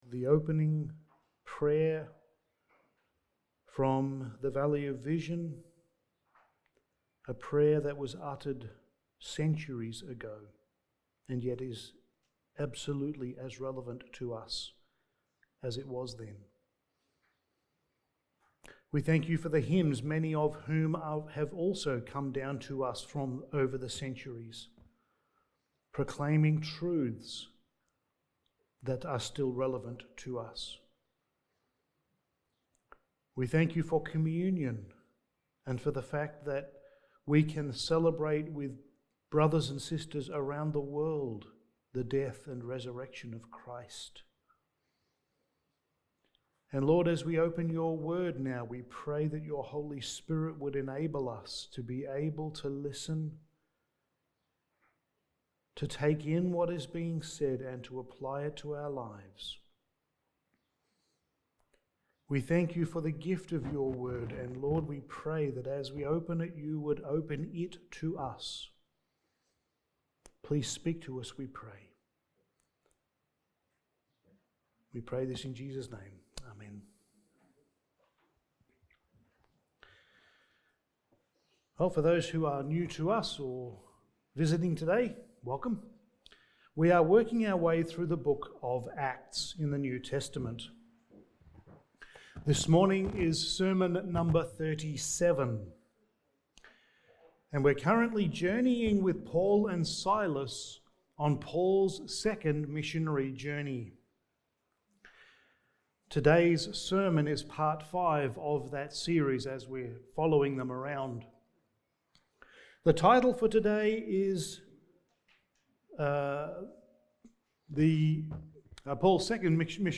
Passage: Acts 17:10-21 Service Type: Sunday Morning